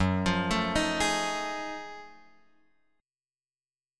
F#mM13 chord {2 x 3 2 4 4} chord